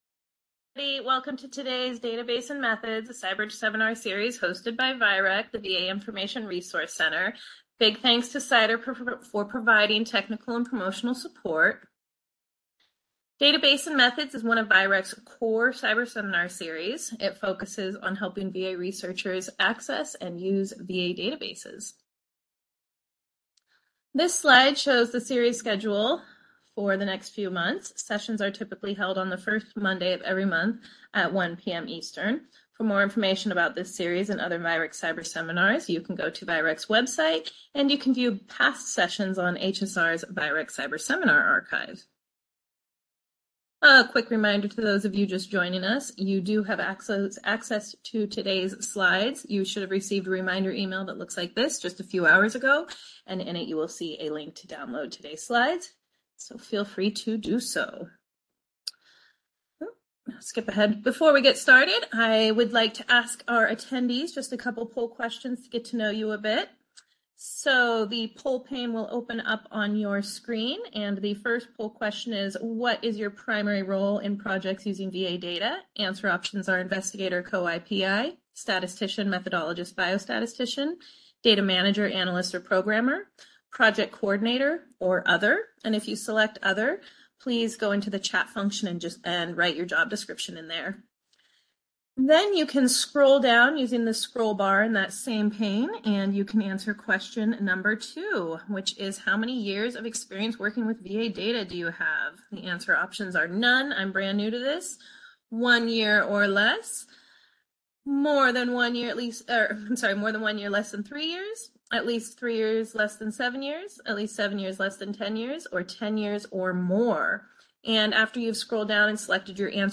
VIReC Database and Methods Seminar